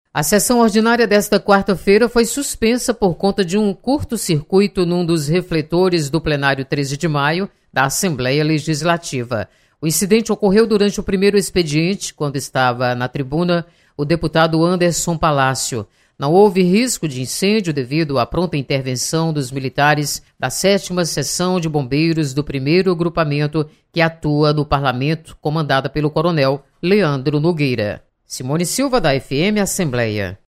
Sessão Plenária desta quarta-feira é suspensa. Detalhes com a repórter